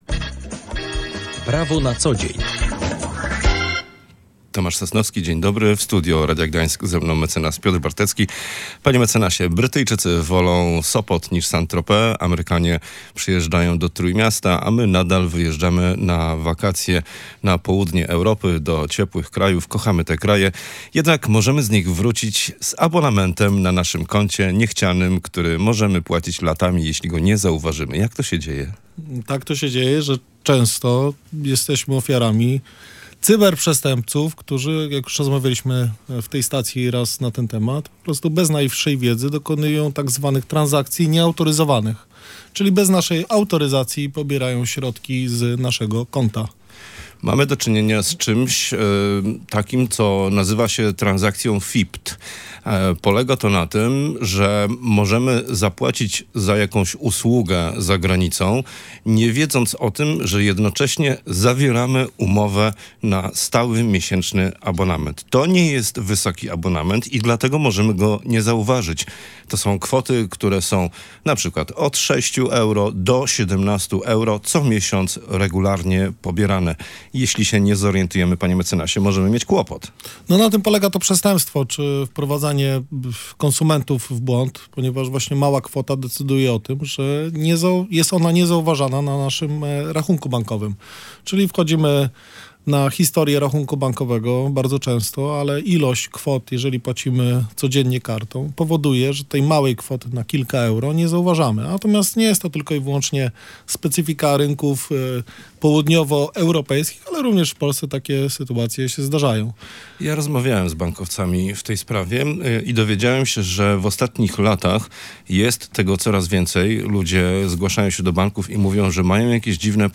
gość audycji „Prawo na co dzień”